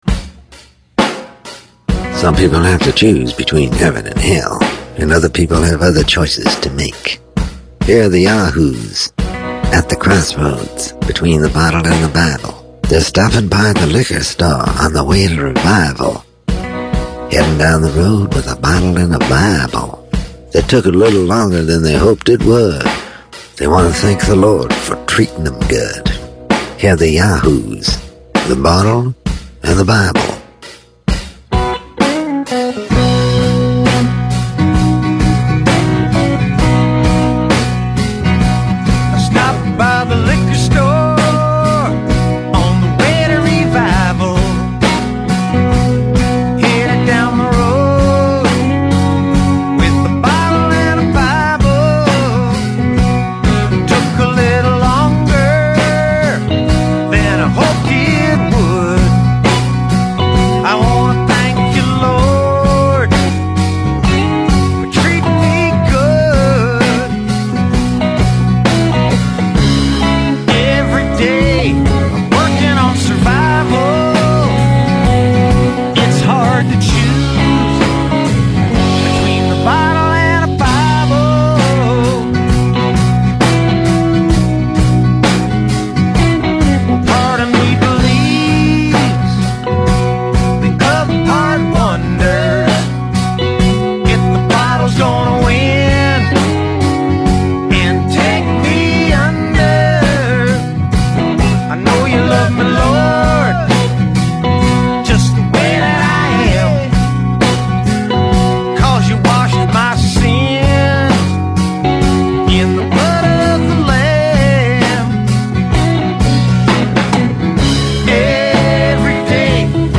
On his radio show